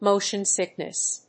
アクセントmótion sìckness